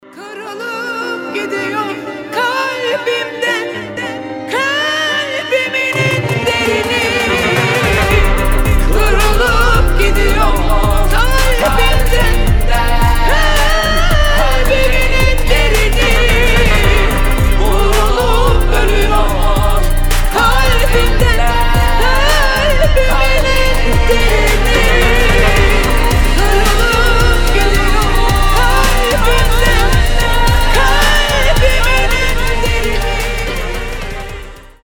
грустные
дуэт